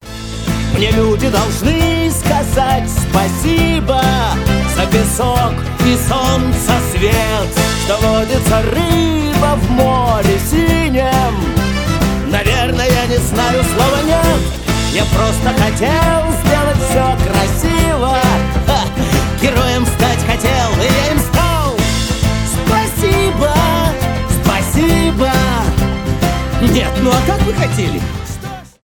из мультфильмов
позитивные